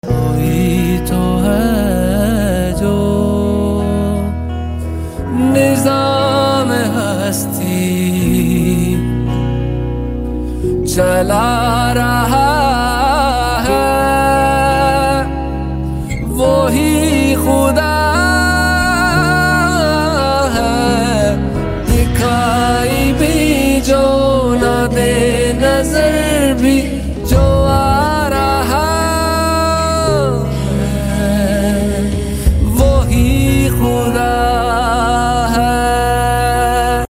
A Single Plane Soaring High, Sound Effects Free Download